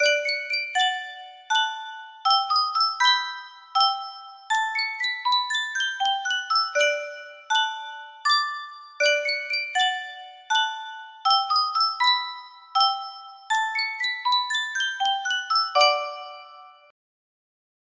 Sankyo Music Box - Beautiful Dreamer DF
Full range 60